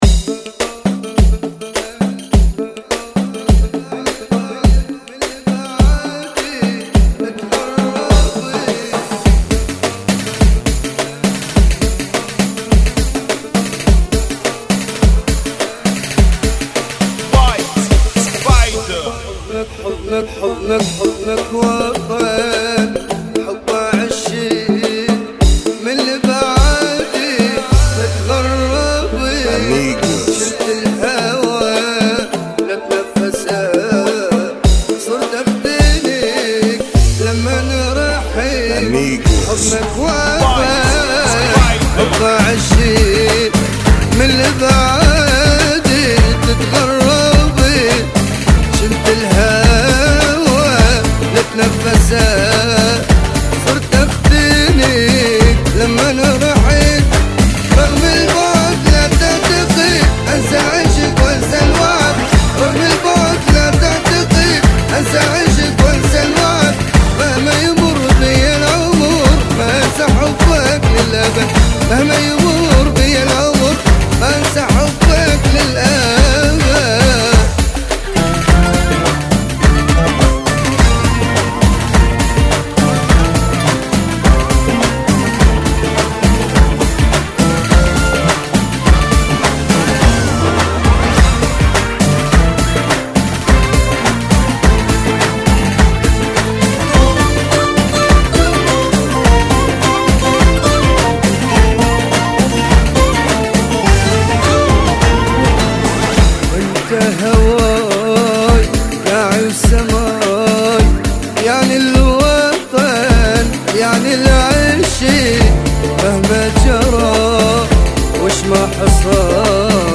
[ 104 Bpm ]
Funky